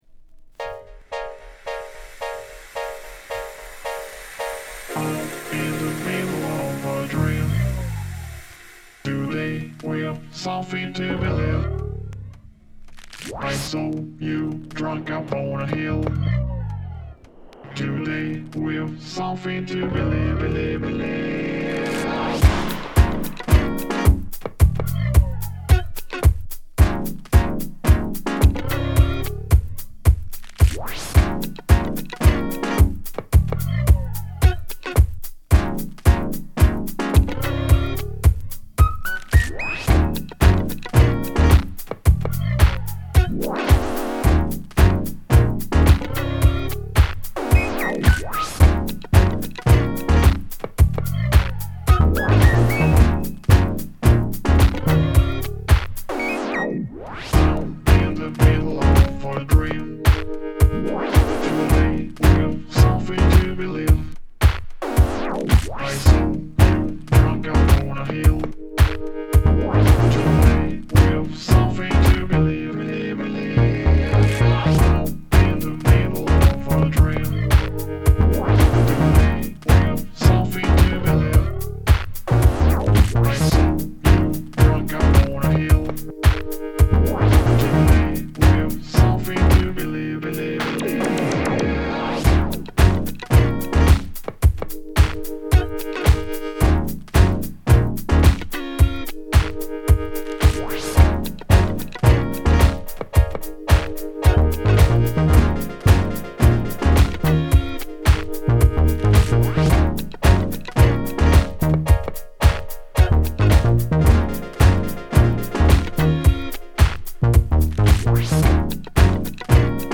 Boogie
Disco